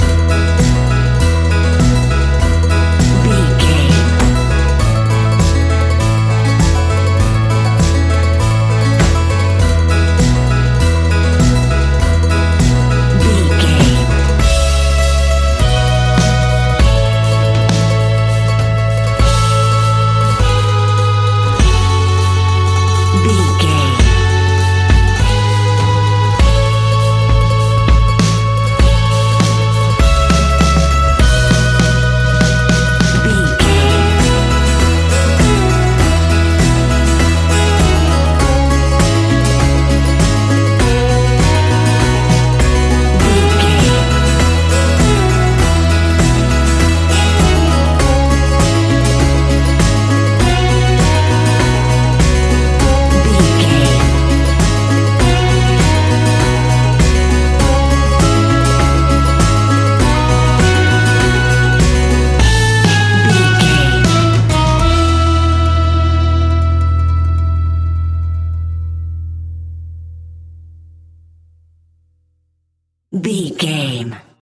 Uplifting
Ionian/Major
acoustic guitar
mandolin
double bass
accordion